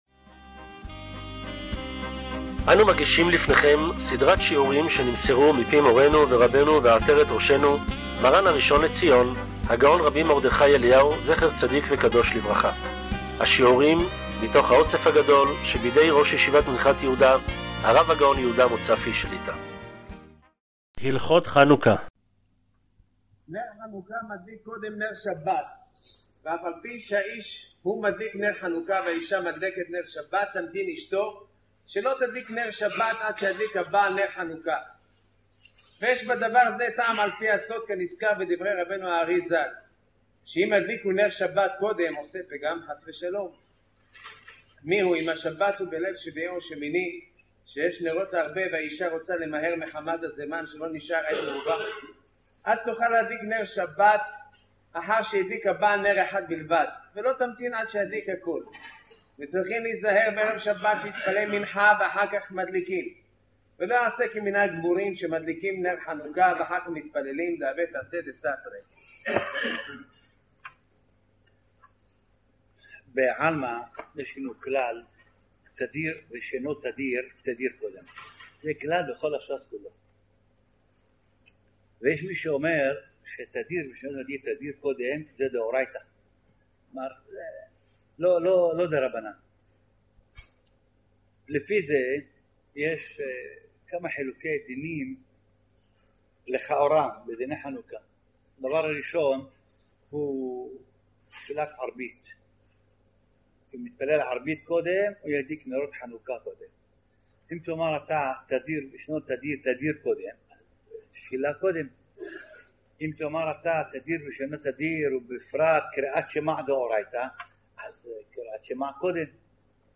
הלכות חנוכה ע"פ 'הבן איש חי' - שיעור מספר 6 - ערוץ מאיר - אתר היהדות הגדול בעולם